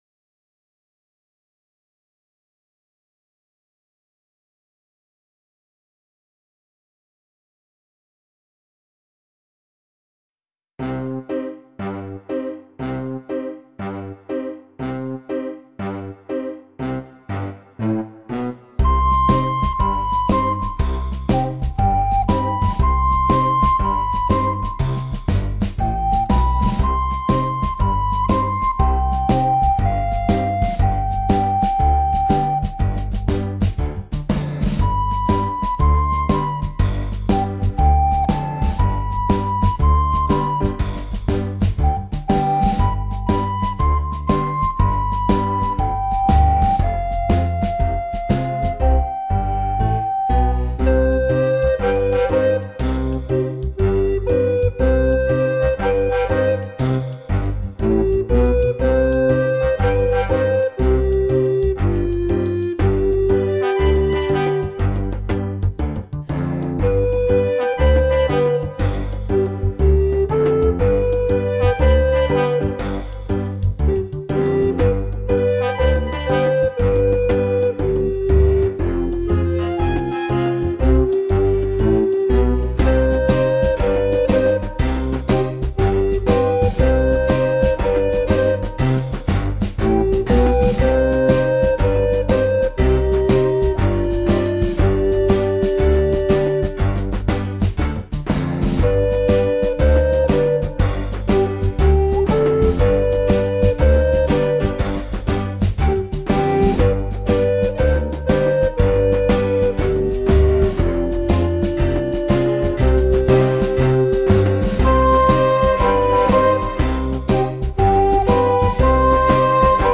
Este é o acompañamento musical completo da canción: